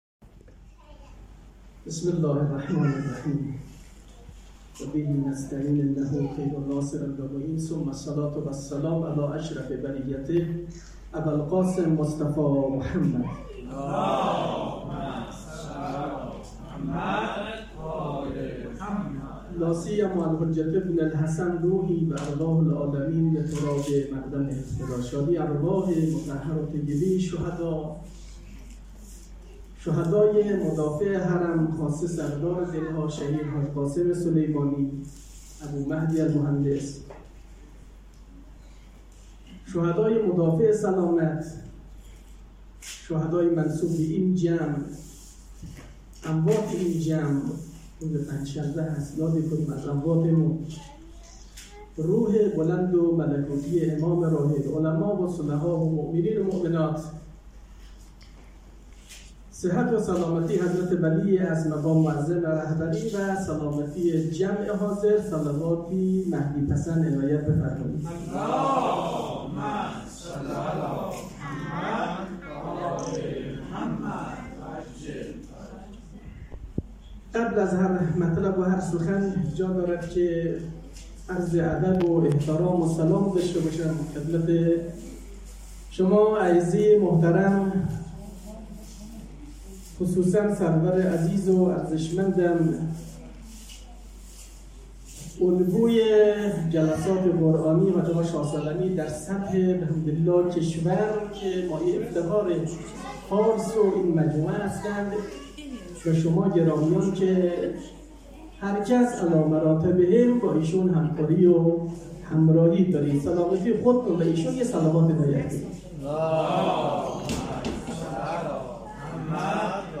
سخنرانی در جمع مسئولان موسسه قرآنی بیت الاحزان حضرت زهرا سلام الله علیها شهرستان نی ریز و بختگان.mp3